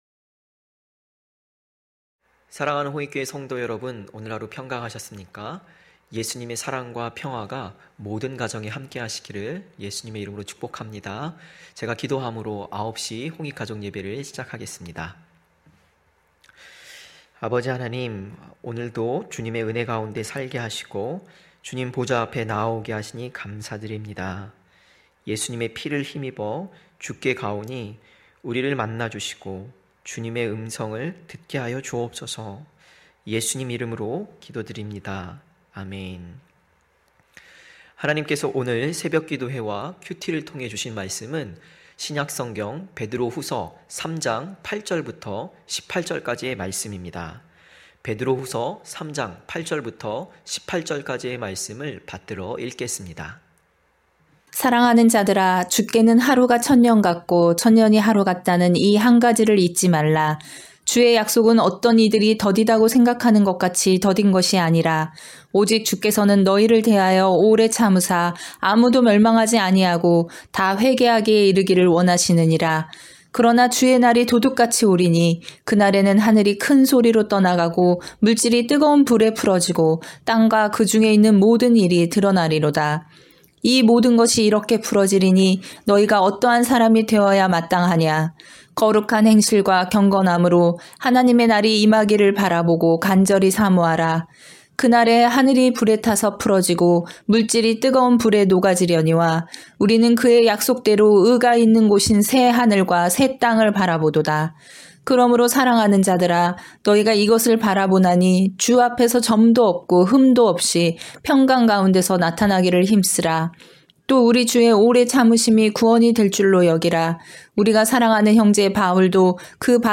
9시 홍익가족예배(12월26일).mp3